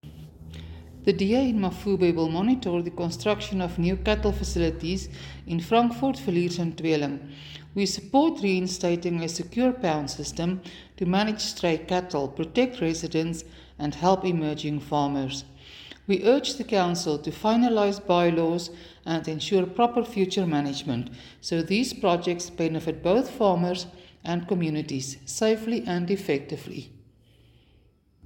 Afrikaans soundbites by Cllr Suzette Steyn and